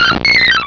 Cri de Joliflor dans Pokémon Rubis et Saphir.